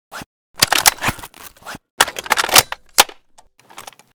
vssk_reload.ogg